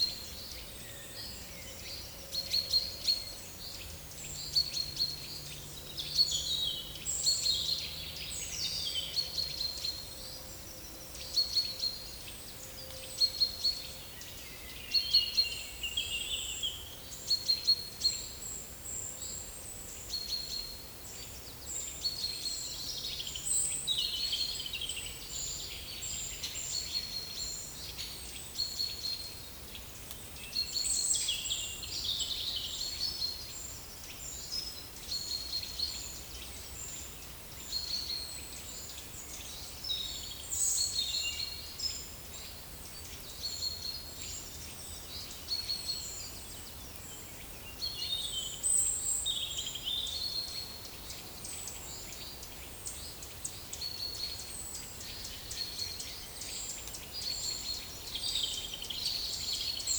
Certhia familiaris
Dryobates minor
Sitta europaea
Certhia brachydactyla
Leiopicus medius
Fringilla coelebs